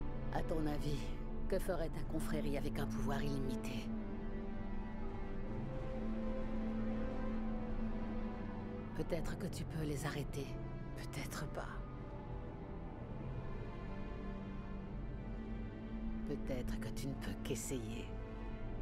Lee Moldaver parlant à [Maximus (série télévisée[Maximus (série télévisée)|Maximus]] dans l'épisode 8 de la sérié télévisée Fallout.